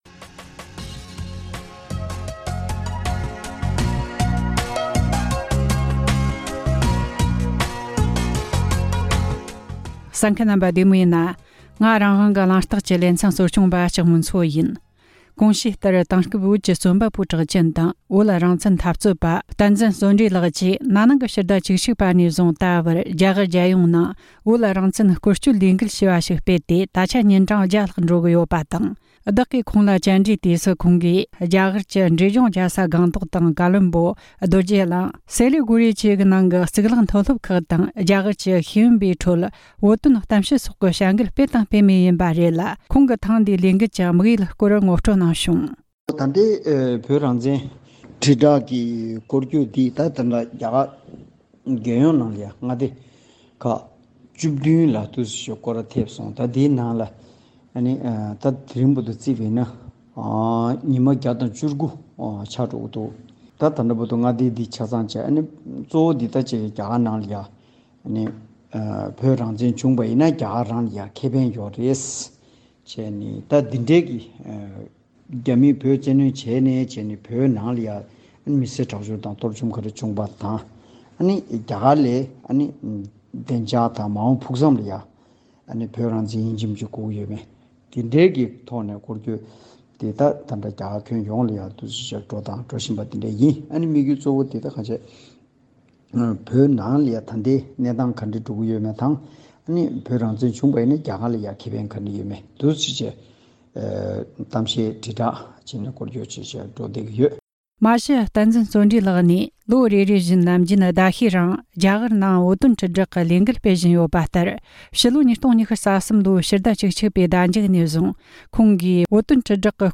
ཕྱོགས་བསྡུས་ཞུས་པའི་གནས་ཚུལ།